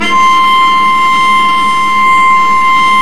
Index of /90_sSampleCDs/Roland - String Master Series/STR_Vc Marc&Harm/STR_Vc Harmonics